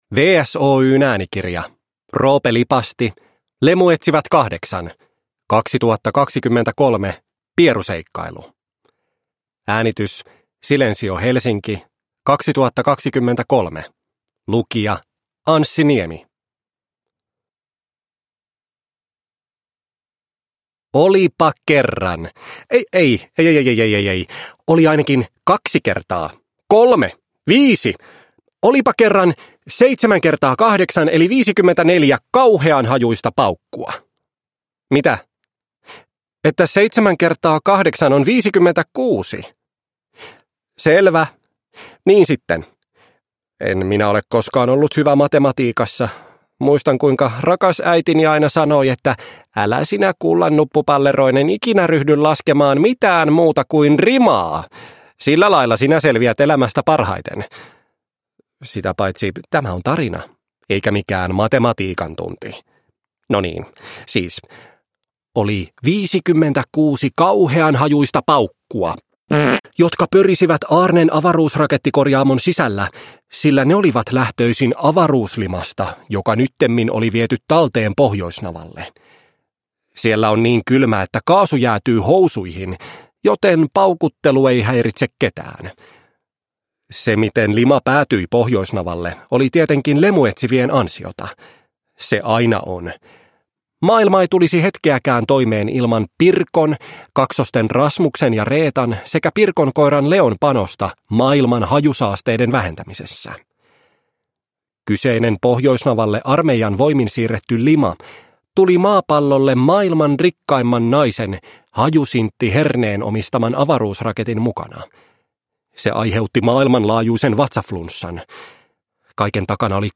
Lemuetsivät 8: 2023 – Pieruseikkailu – Ljudbok – Laddas ner